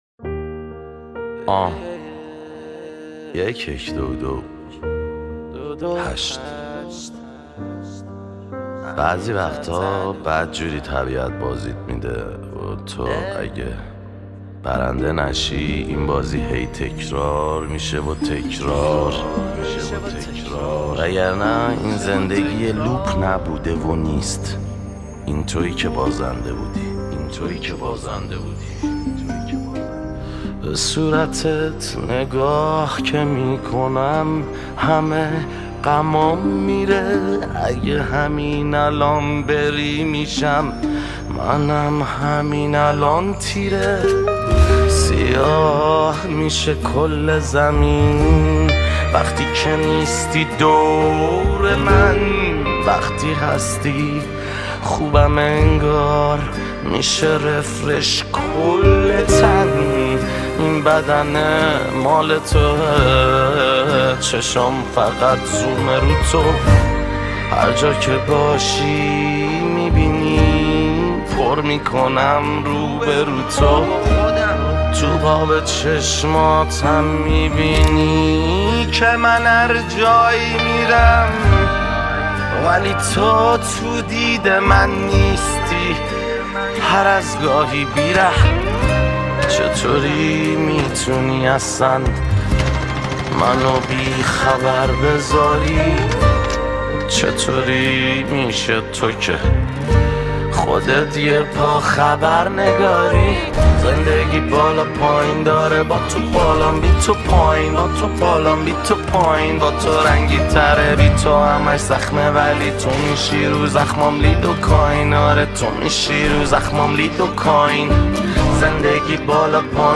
آراَندبی